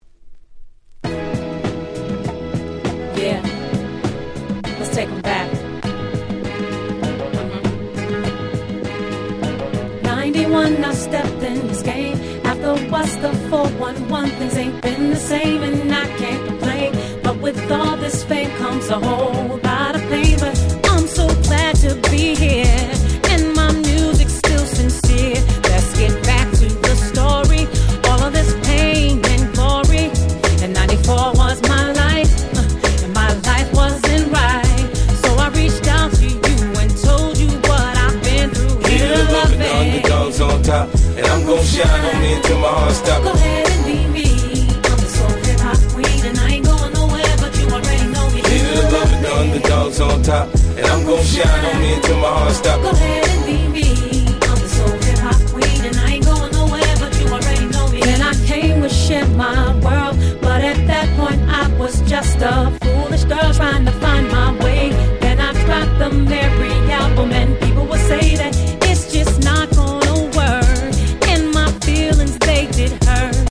05' Super Nice R&B !!